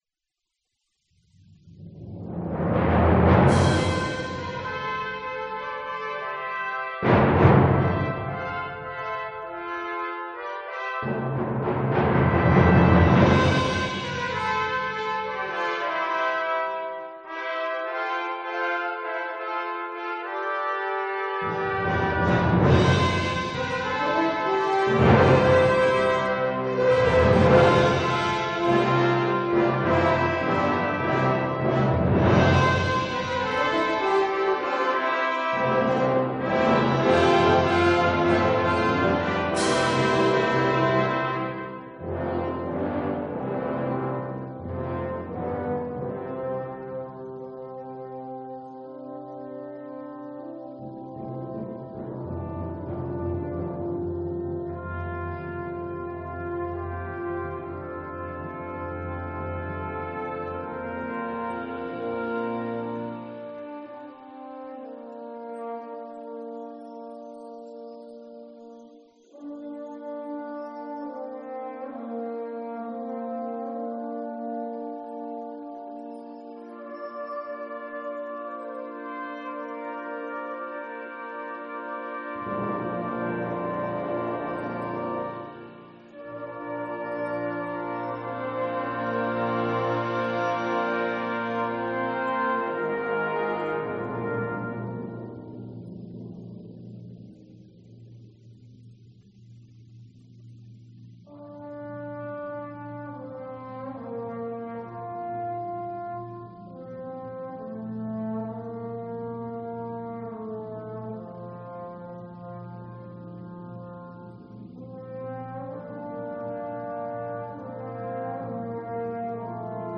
großes Blechbläserensemble